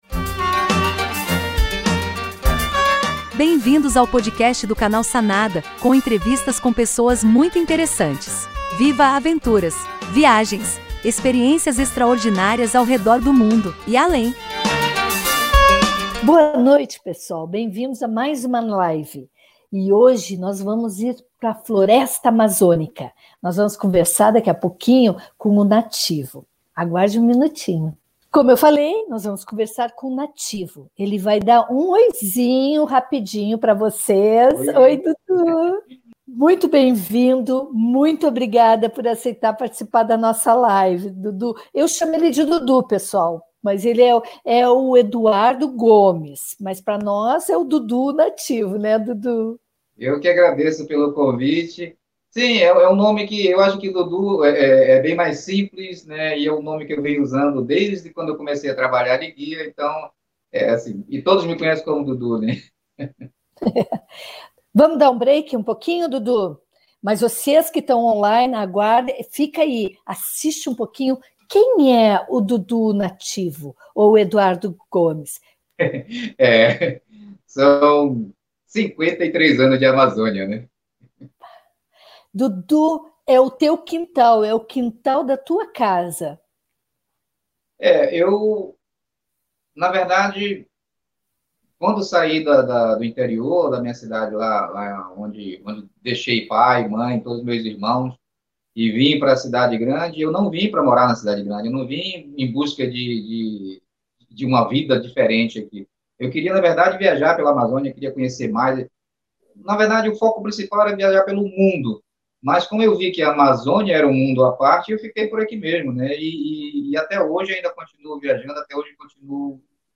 Entrevistas incríveis ao VIVO todas as terças-feiras às 19h30 no Canal Sanada do YouTube.